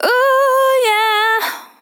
Categories: Vocals Tags: dry, english, female, fill, LOFI VIBES